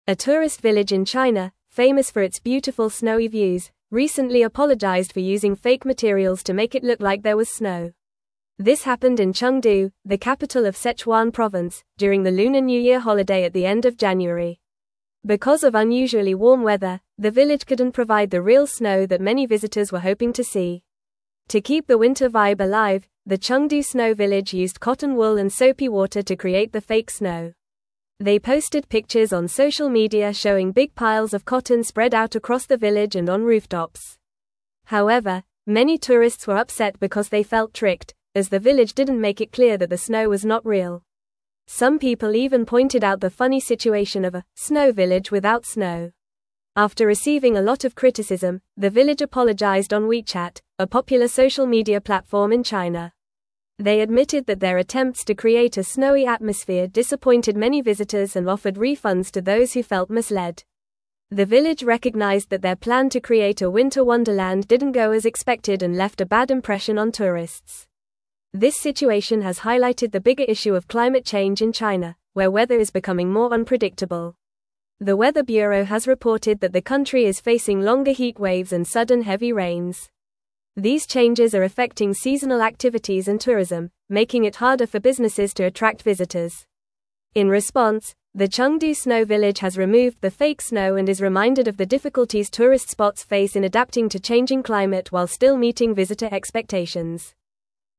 Fast
English-Newsroom-Upper-Intermediate-FAST-Reading-Chengdu-Village-Apologizes-for-Fake-Snow-Misleading-Tourists.mp3